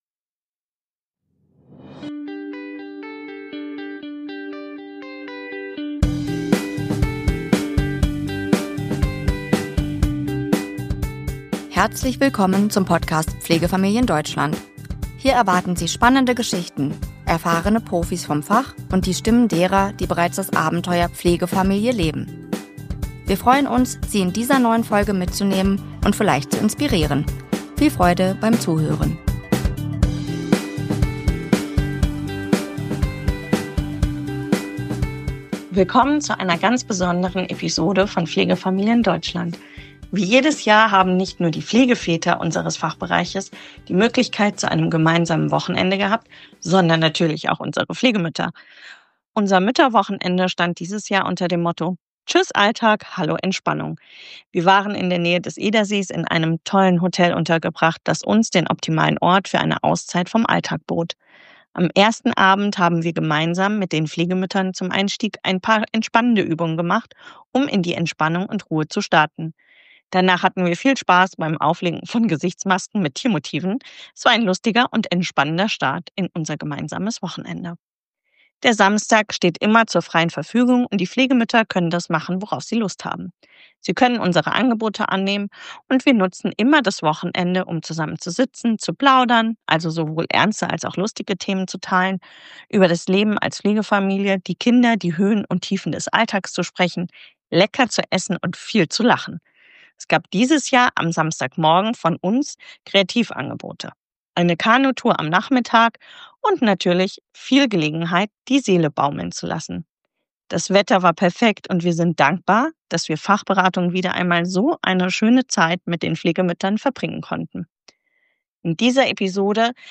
** Ungeschliffener Sound: Diese Episode wurde unterwegs und mit Hintergrundgeräuschen aufgenommen.
Bitte stören Sie sich nicht an den Geräuschen, sondern fühlen Sie sich einfach wie live dabei!**